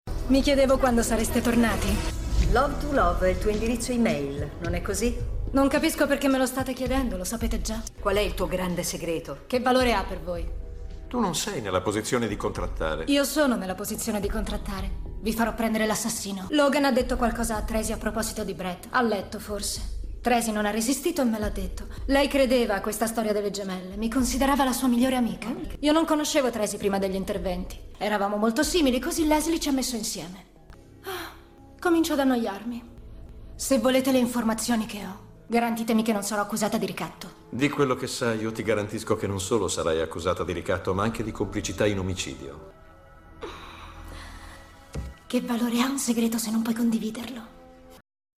nel telefilm "Numb3rs", in cui doppia Kelly Overton.